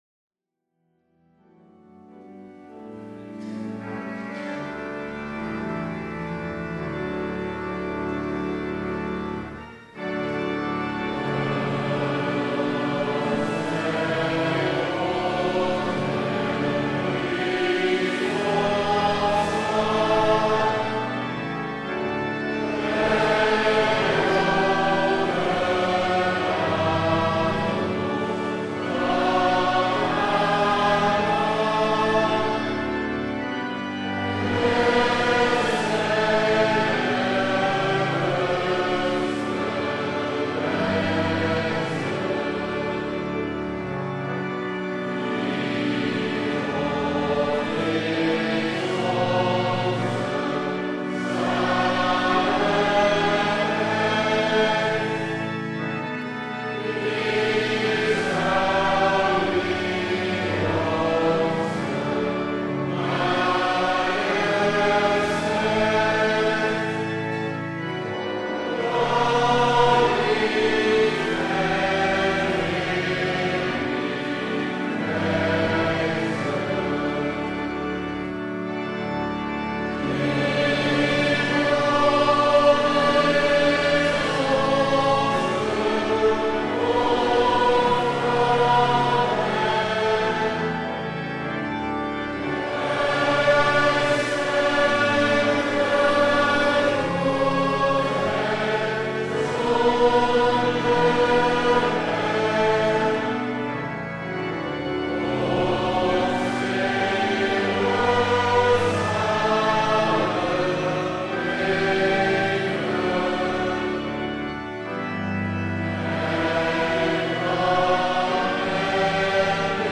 Eredienst